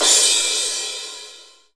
SCRASH LW.wav